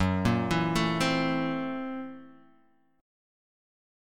Gb7#9b5 Chord